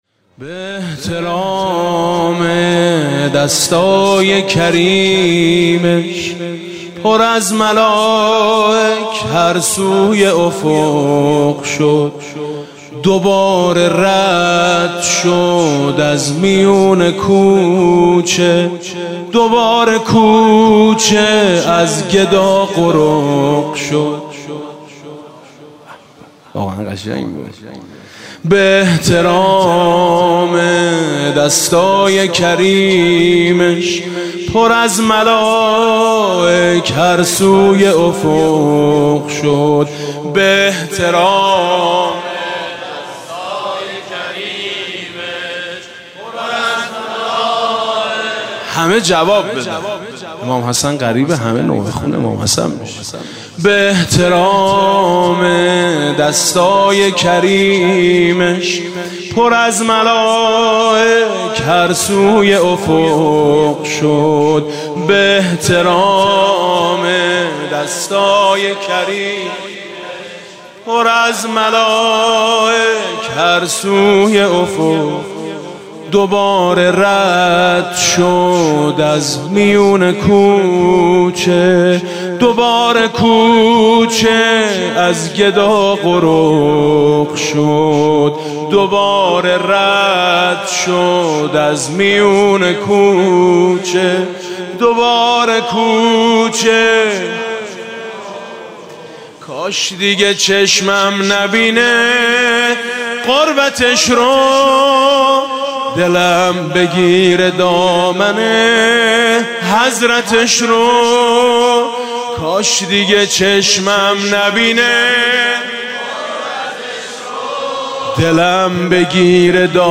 «شب پانزدهم رمضان 1397» سرود: می شینه پای سفره یتیما